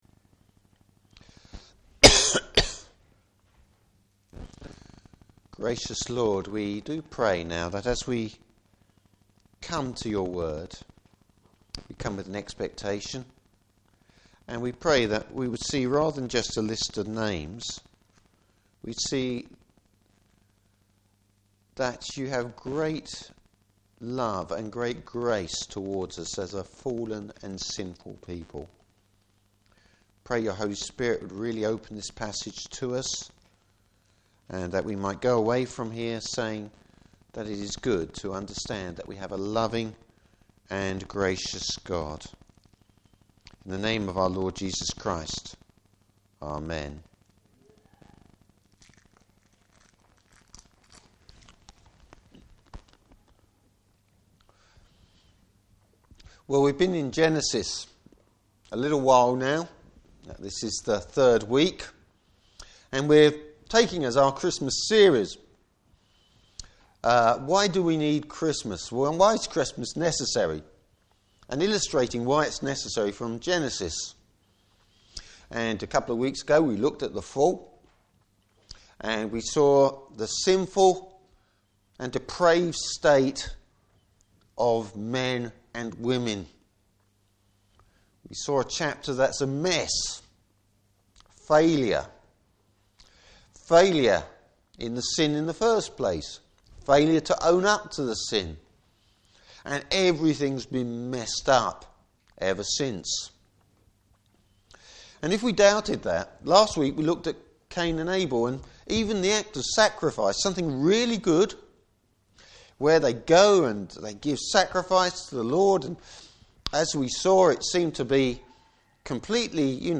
Service Type: Morning Service Death rules the world, but there’s a glimmer of grace!